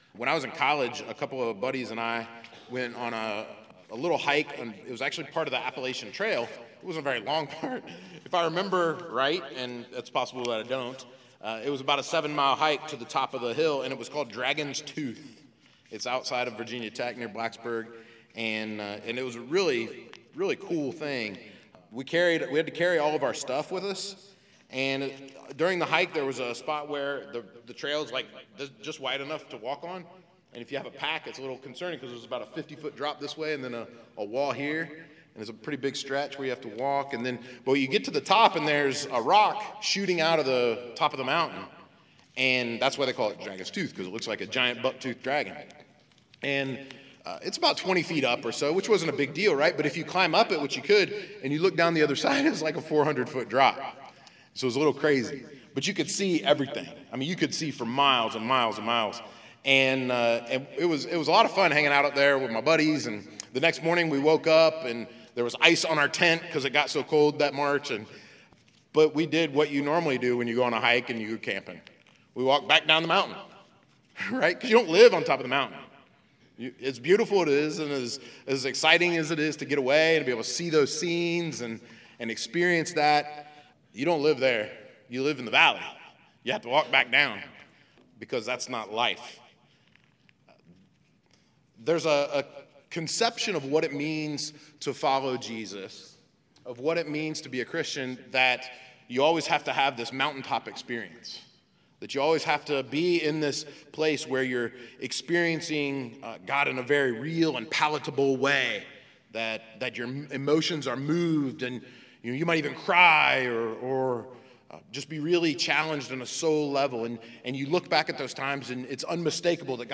gods-guide-for-lifes-experiences-sermon-6-psalm-100.mp3